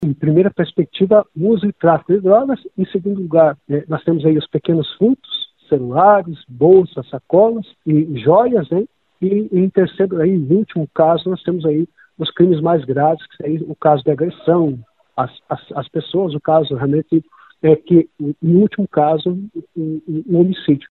O professor e especialista em Segurança Pública